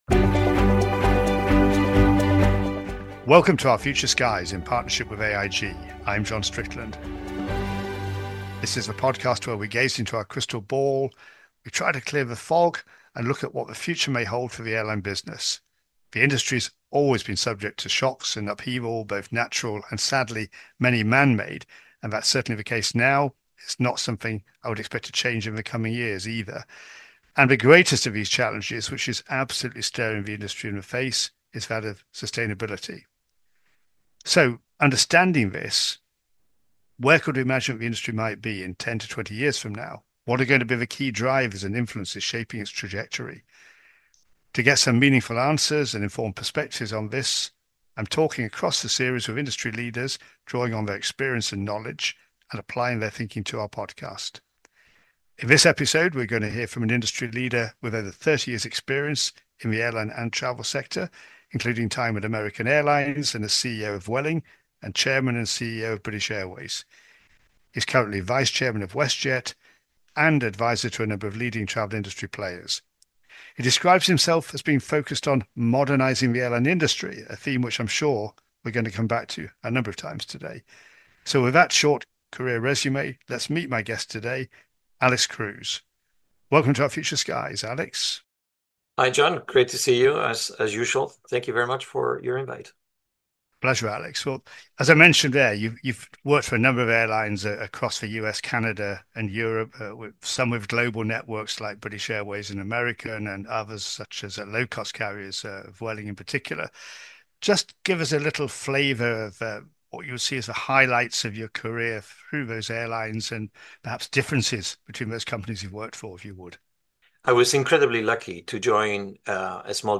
In conversation with Álex Cruz